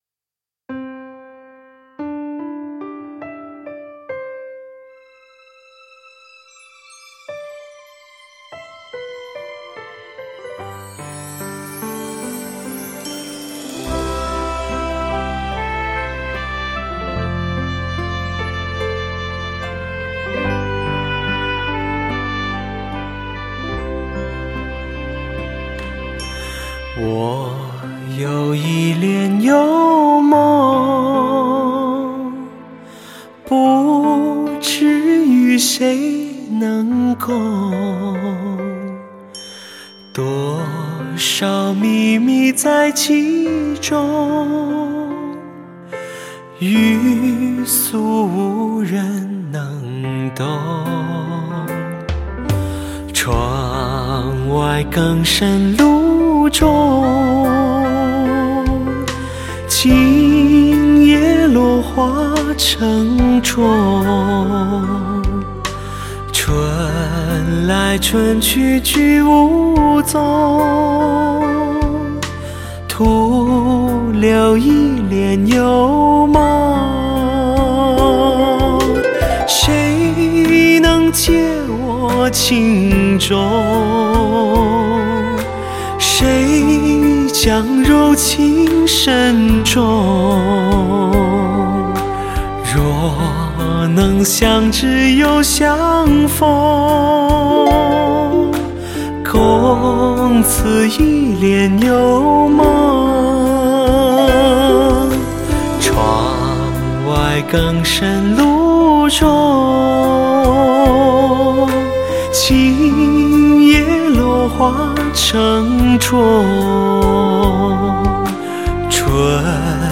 精选15首最通透的极品人声！
精挑细选最畅销的华语流行热曲，展现汽车发烧音响大碟最顶尖的录音水准，